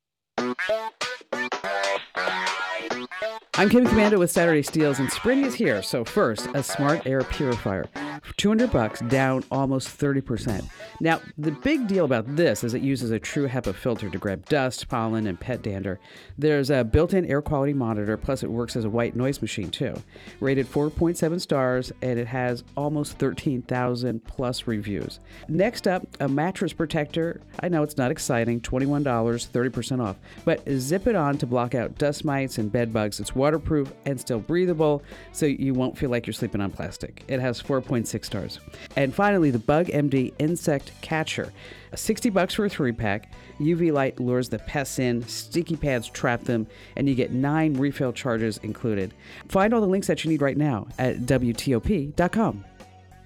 Kim Komando breaks down the top deals